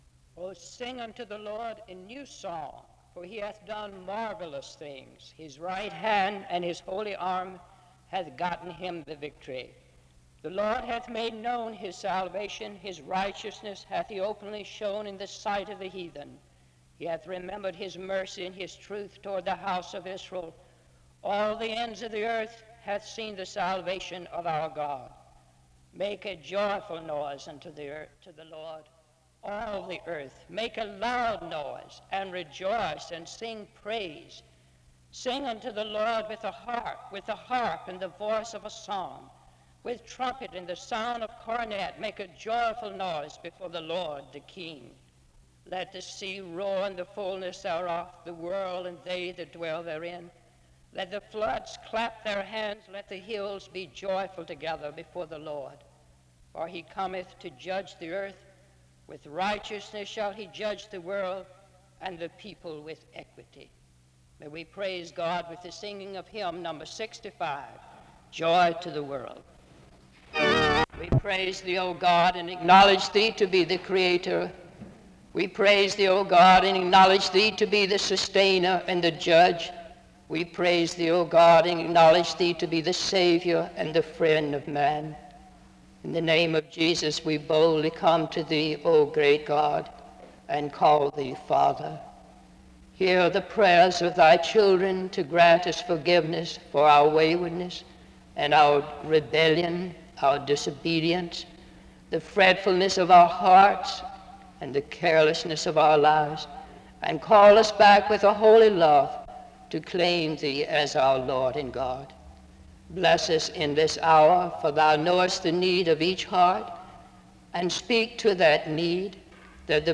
The service begins with a scripture reading and prayer (0:00-2:42).
The service closes in prayer (27:44-28:08). This chapel is distorted from 0:00-1:27.